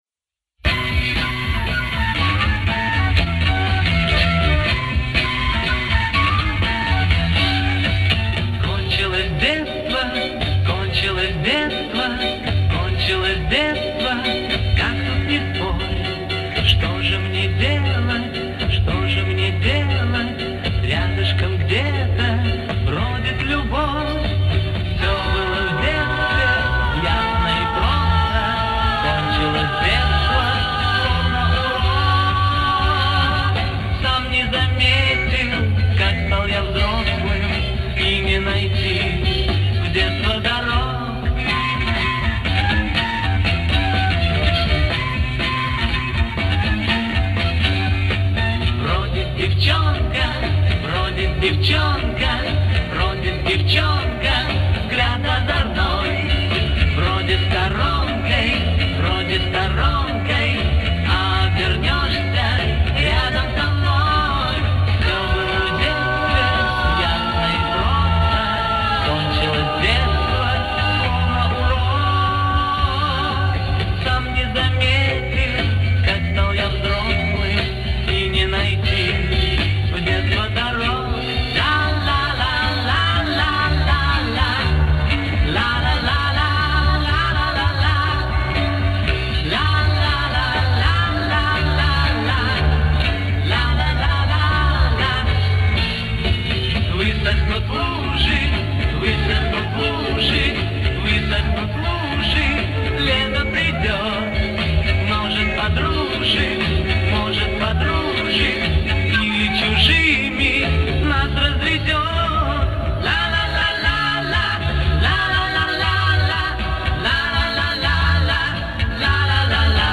Мой вариант реставрации .